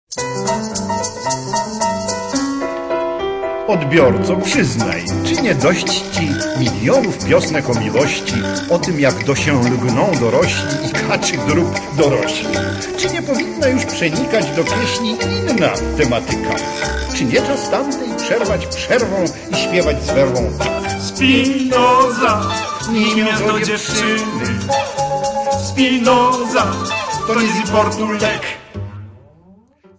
40 Hit Polish Cabaret Songs